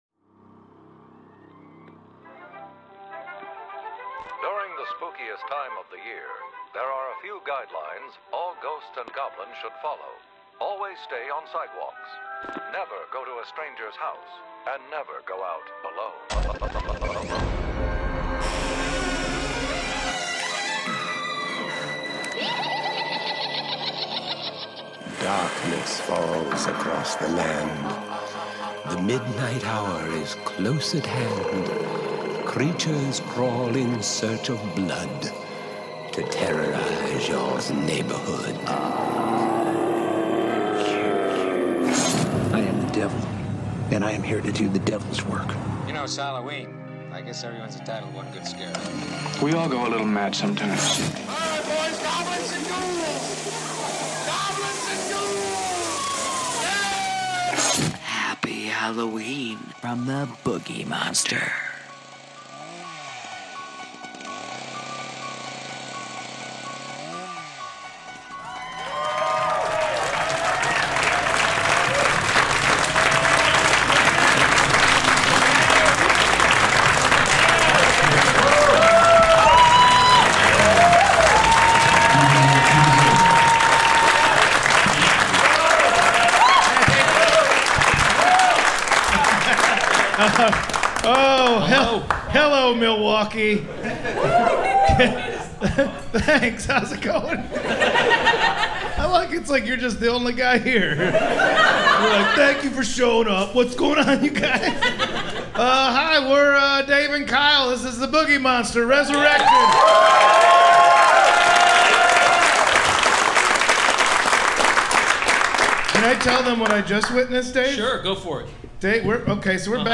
Live from Milwaukee